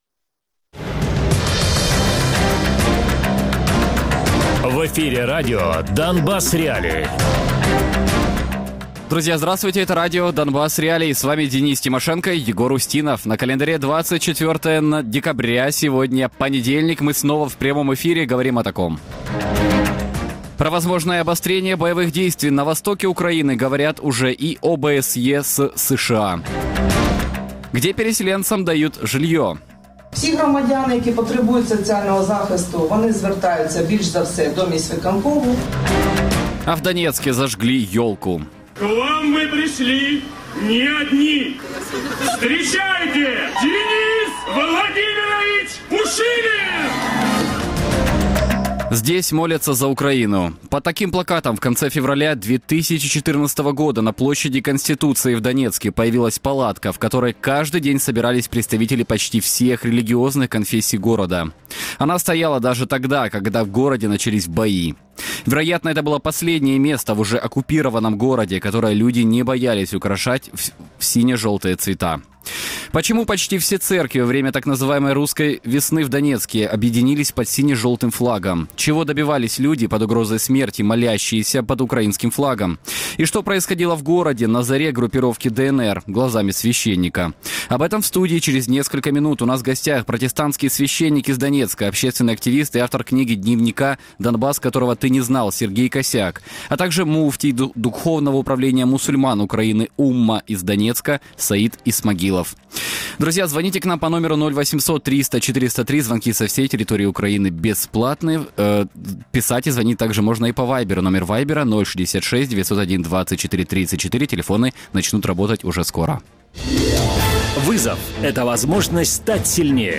Радіопрограма «Донбас.Реалії» — у будні з 17:00 до 18:00. Без агресії і перебільшення. 60 хвилин про найважливіше для Донецької і Луганської областей.